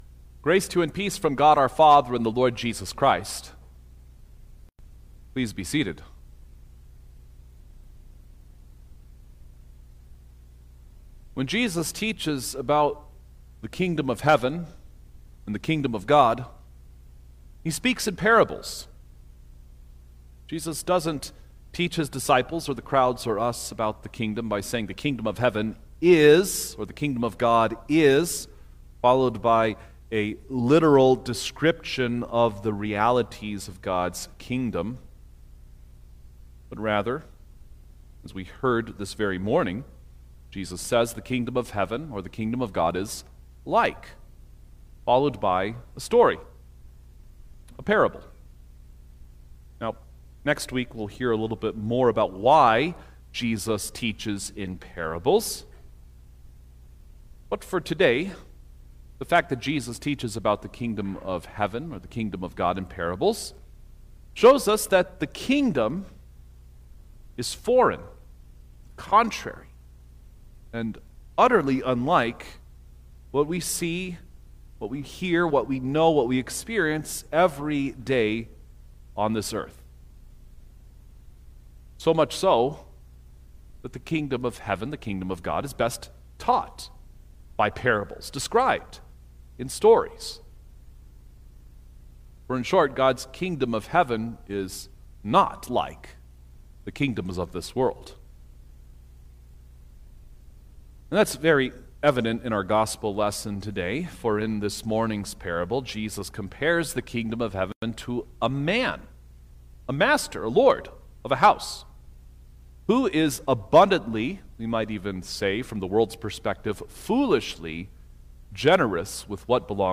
February-1_2026_Septuagesima_Sermon-Stereo.mp3